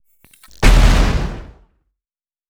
Grenade10.wav